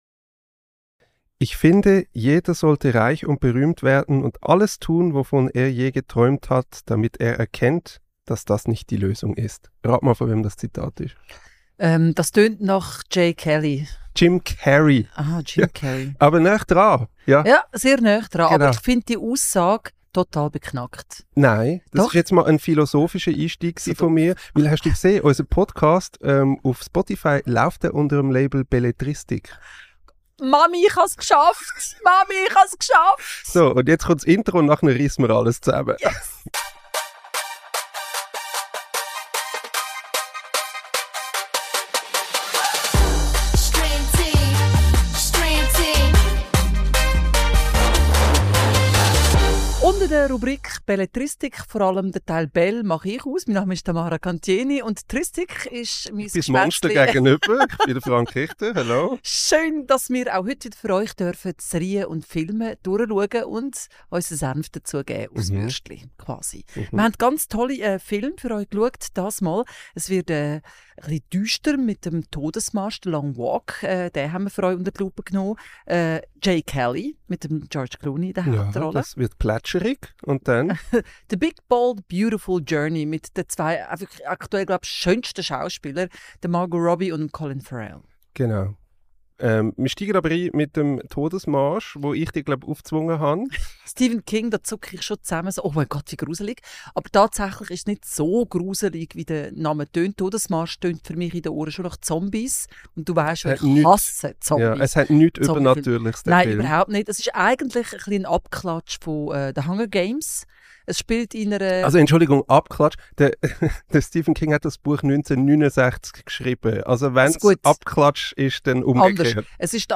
Episode 7 | The Long Walk, A Big Bold Beautiful Journey, Jay Kelly ~ Streamteam | Der erste Film- und Serienpodcast auf Schweizerdeutsch Podcast